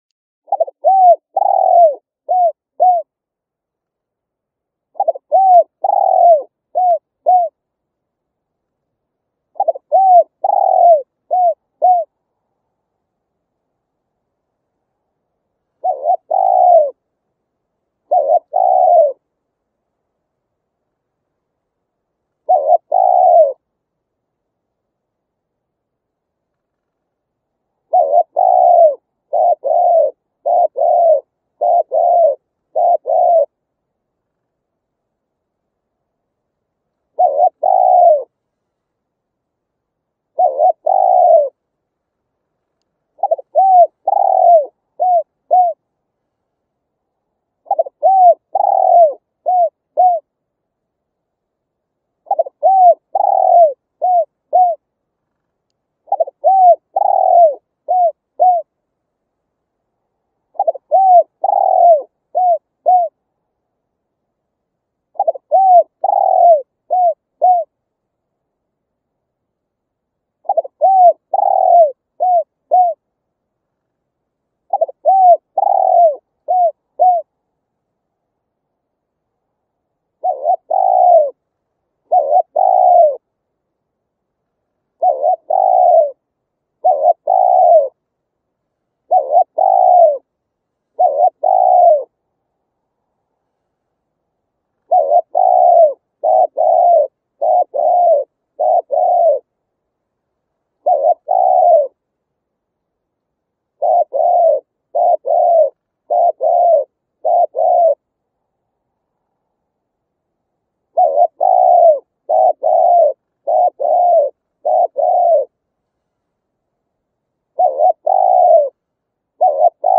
Tải tiếng chim cu gáy 2 hậu mp3 với âm thanh chất lượng cao, không có tạp âm. Tiếng chim cu gáy mang đến cảm giác thanh bình, thích hợp cho các dự án về thiên nhiên, nông thôn hoặc thư giãn.
Tiếng cu gáy 2 hậu
tieng-cu-gay-2-hau-www_tiengdong_com.mp3